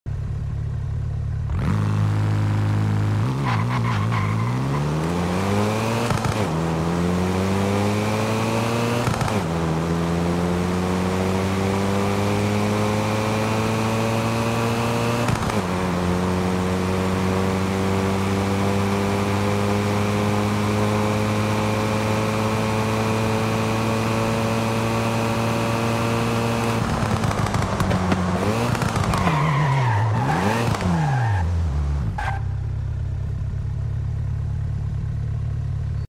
2020 Hyundai i30 N Launch sound effects free download
2020 Hyundai i30 N Launch Control & Sound - Forza Horizon 5